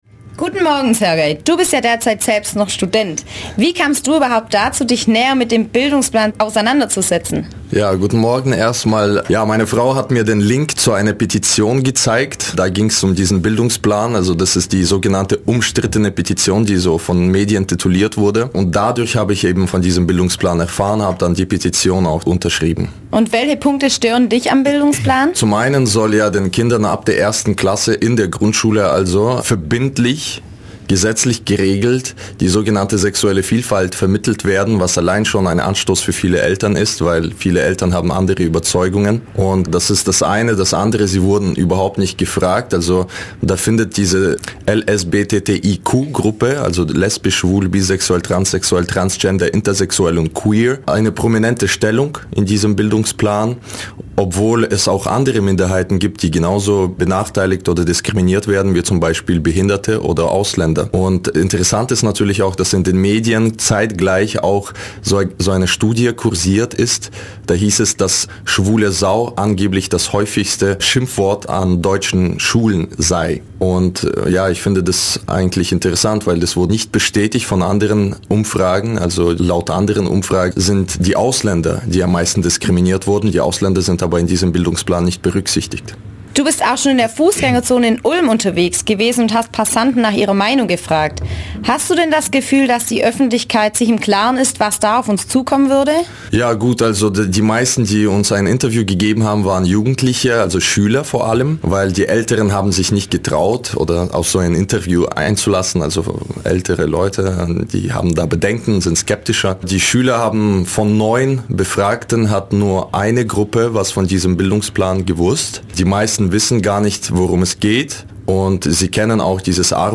Unter anderem hat er selbst eine Umfrage über die Meinungen zum Bildungsplan gestartet, von welcher er auch im Interview berichtet.